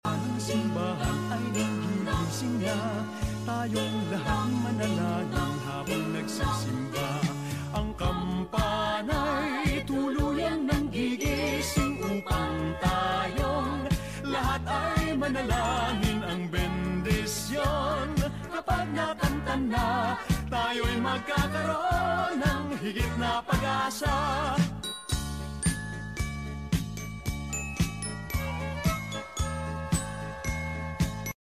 Kampana Ng Simbahan 🎄⛄ Map:rh Sound Effects Free Download